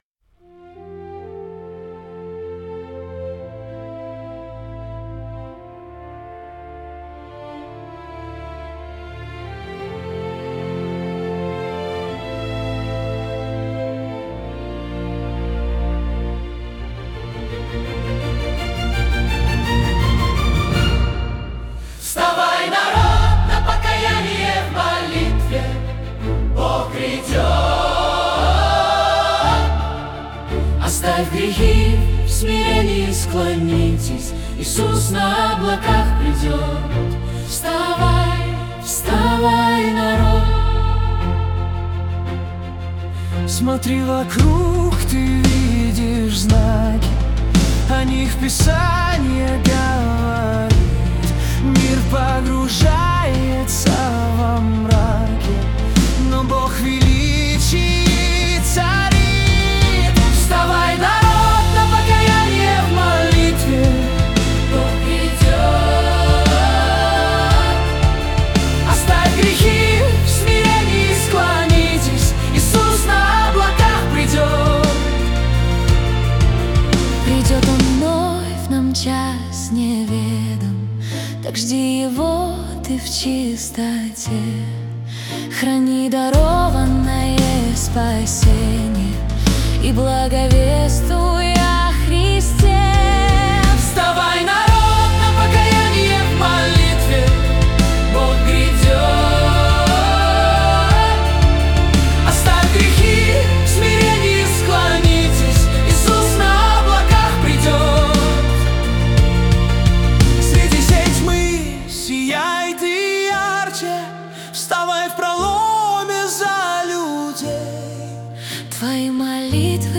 песня ai
33 просмотра 175 прослушиваний 9 скачиваний BPM: 57 4/4